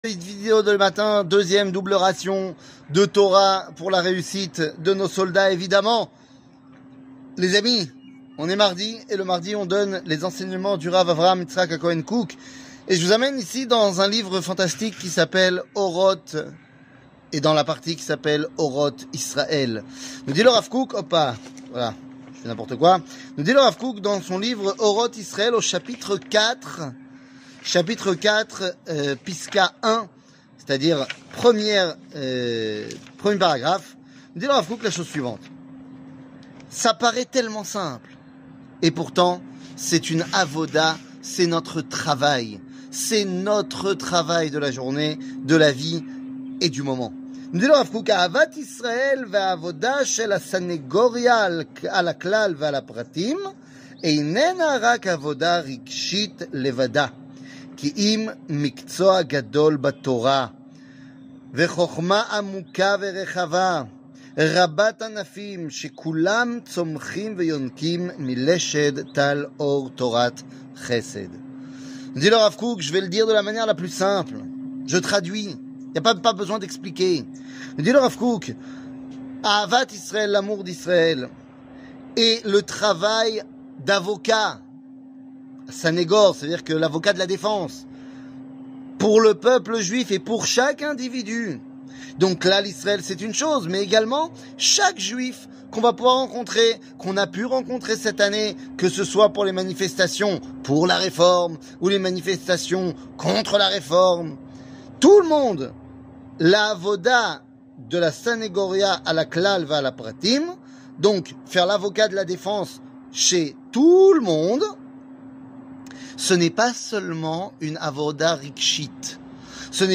שיעור מ 17 אוקטובר 2023